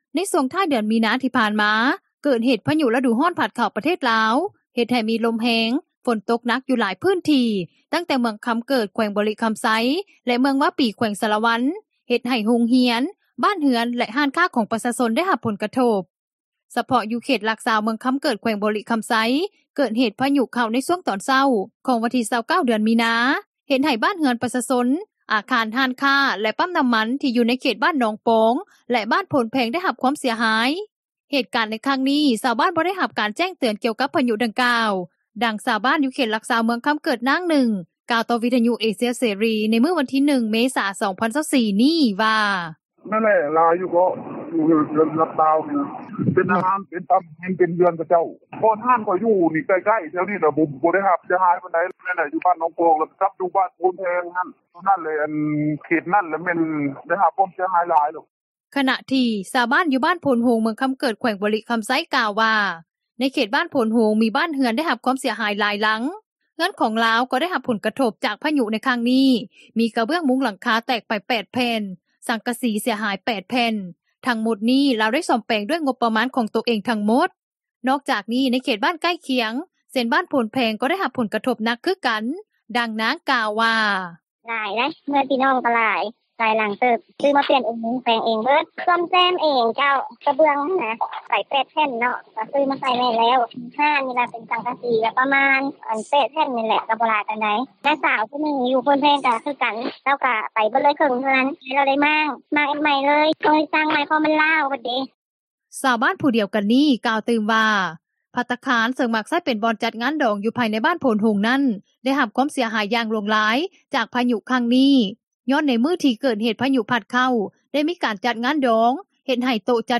ດັ່ງເຈົ້າໜ້າທີ່ ຫ້ອງວ່າການປົກຄອງເມືອງຄໍາເກີດ ນາງນຶ່ງ ກ່າວວ່າ:
ດັ່ງເຈົ້າໜ້າທີ່ເມືອງວາປີ ແຂວງສາລະວັນ ທ່ານນຶ່ງ ກ່າວວ່າ: